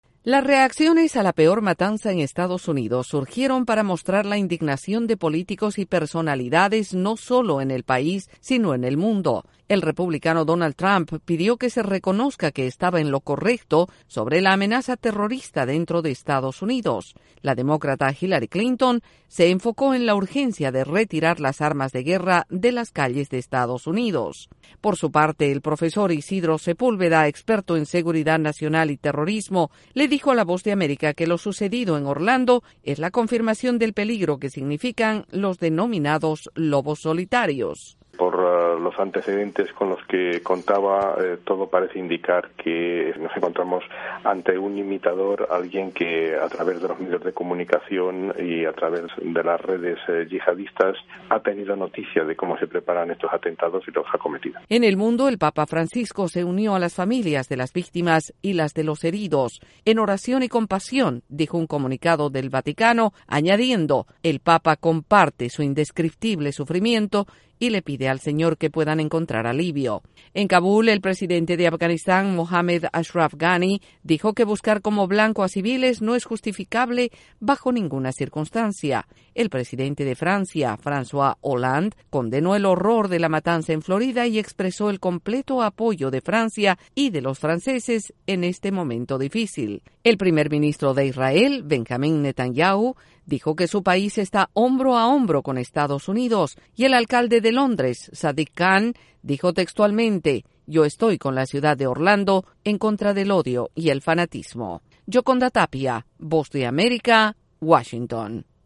Las reacciones a la masacre de Orlando surgieron en Estados Unidos y el mundo mientras un experto afirma que este es un peligro latente. Desde la Voz de América en Washington DC informa